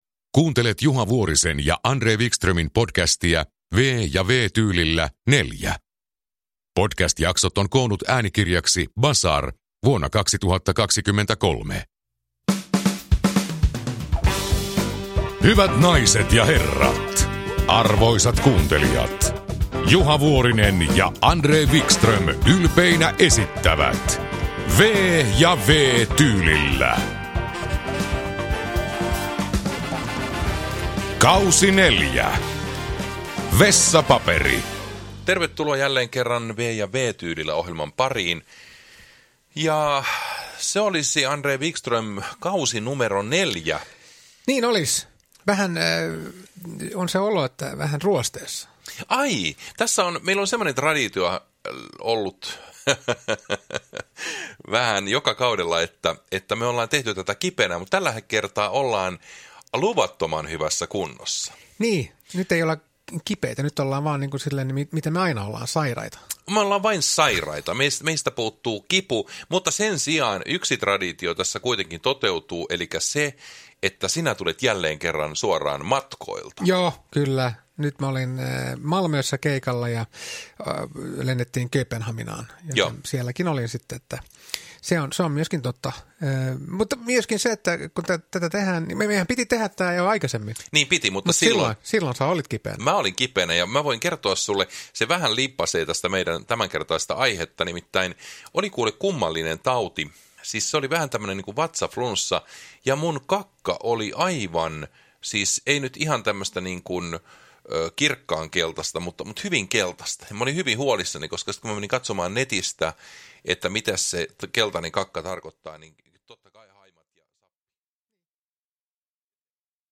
V- ja W-tyylillä K4 – Ljudbok
Humor Memoarer & biografier Njut av en bra bok
Uppläsare: Juha Vuorinen, André Wickström